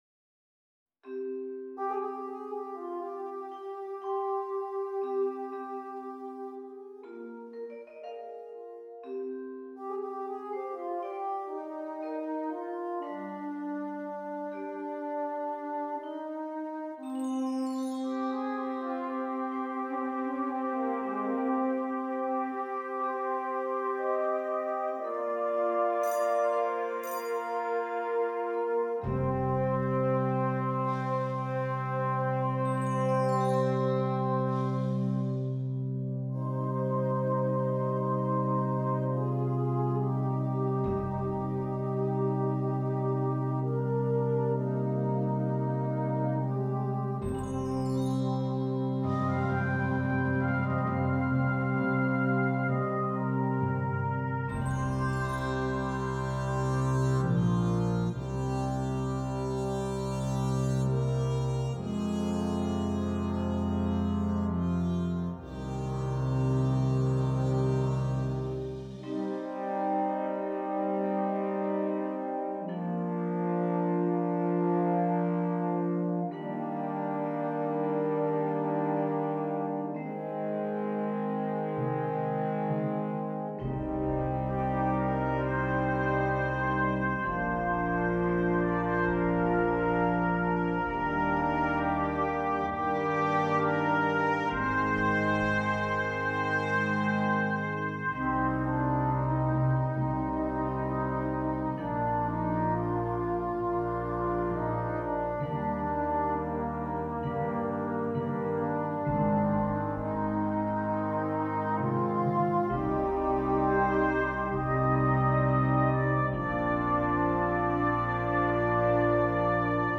Brass Choir